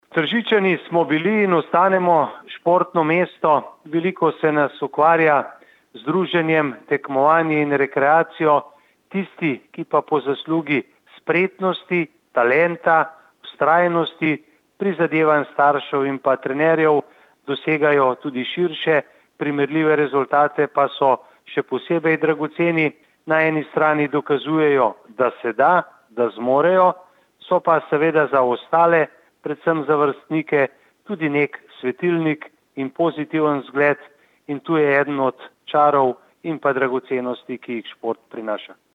izjava_mag.borutsajoviczupanobcinetrzicosportu.mp3 (940kB)